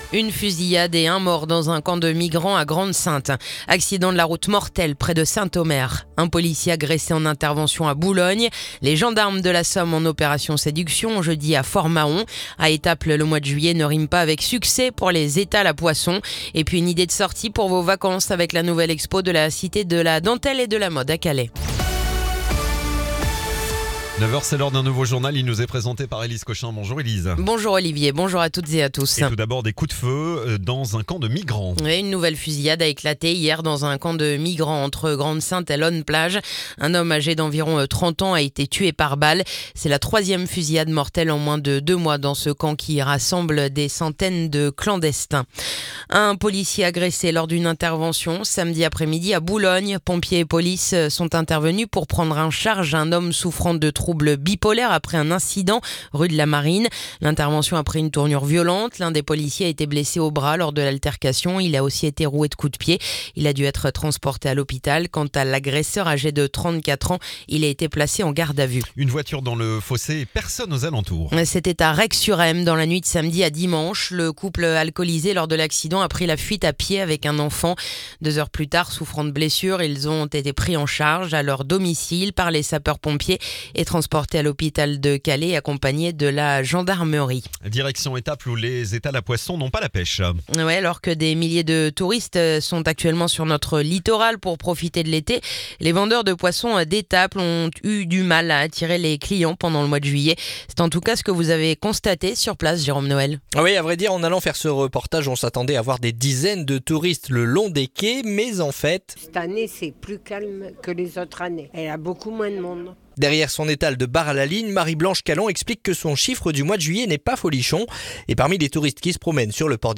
Le journal du lundi 28 juillet